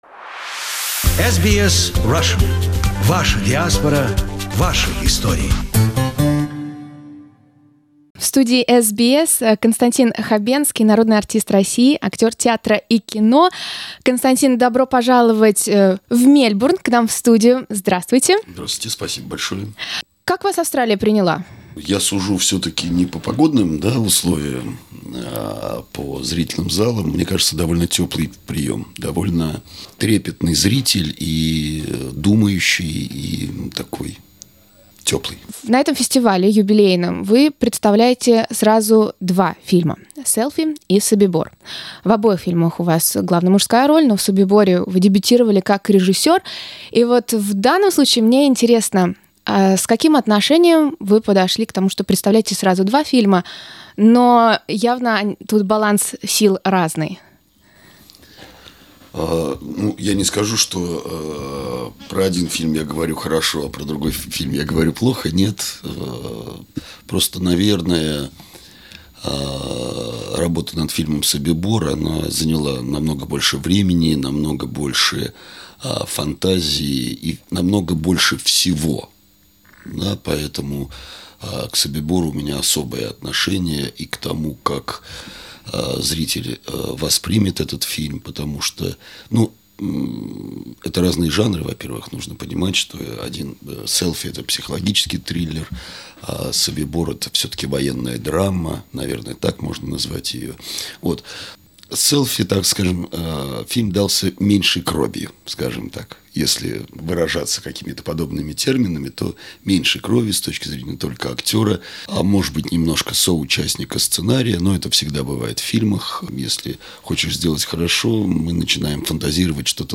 Известный российский актер Константин Хабенский посетил нашу студию, где мы поговорили о его режиссерском дебюте в "Собиборе" и его недавней роли Владимира Богданова в "Селфи", о непростой задаче показать историю концентрационного лагеря и о вопросах, которыми задается сам актер - почему люди начинают симпатизировать отрицательным героям. Это первая из двух частей интервью с Константином.
Константин Хабенский на радио SBS Source: SBS Russian